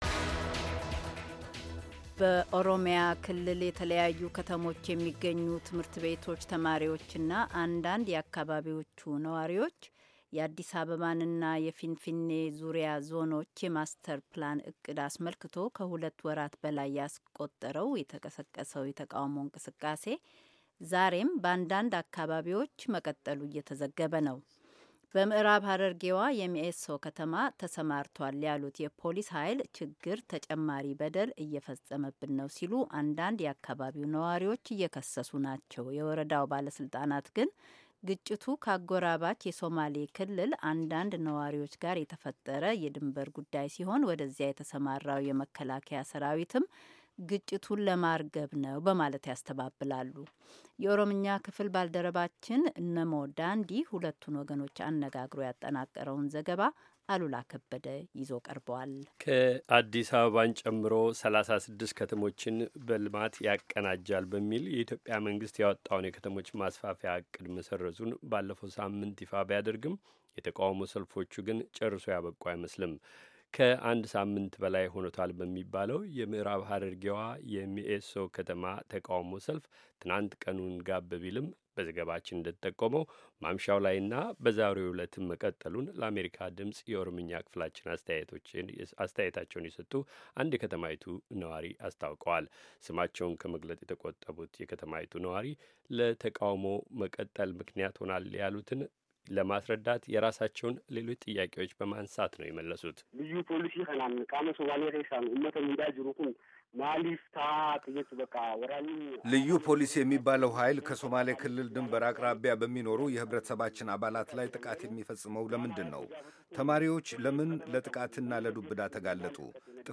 ሁለቱን ወገኖች አነጋግሮ ያጠናቀረውን ዘገባ